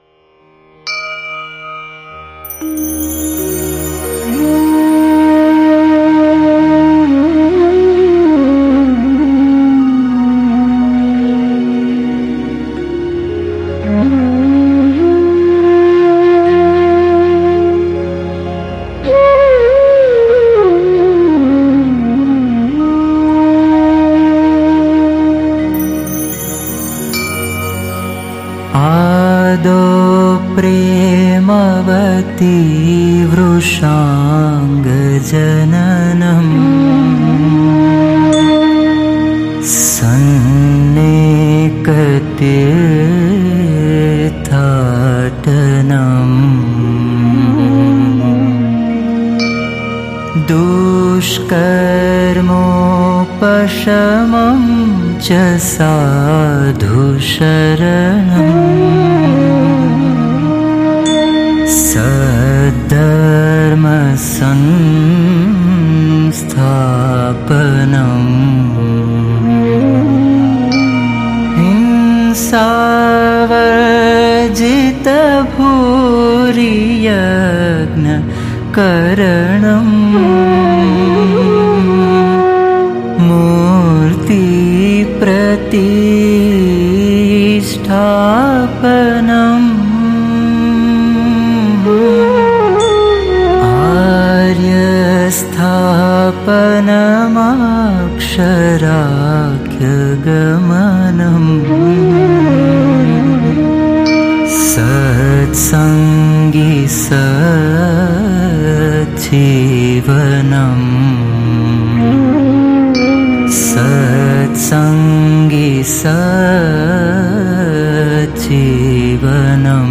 02-Shloka1-swaminarayan-kirtan.mp3